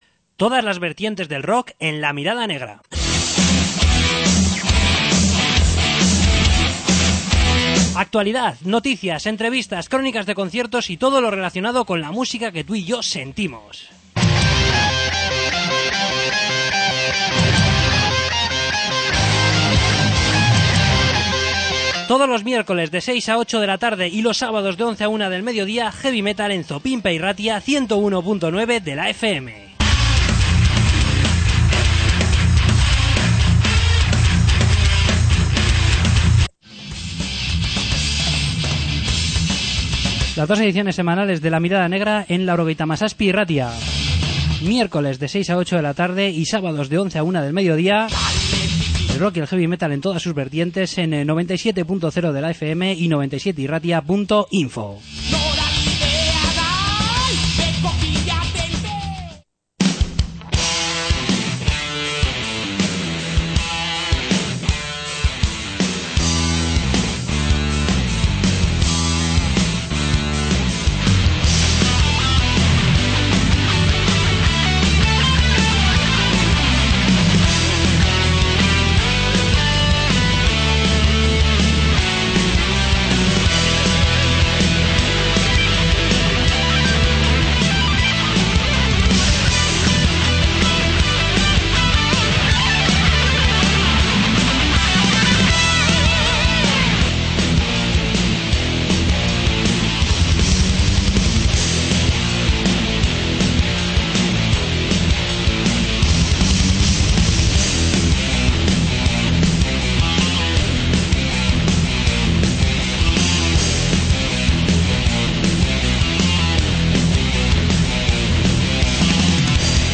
Entrevista con Triple Zero Band